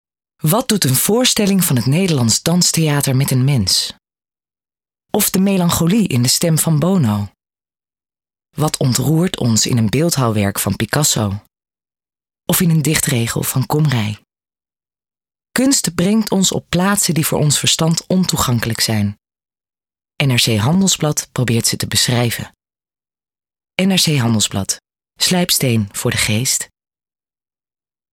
Commercials:
NRC (serieus):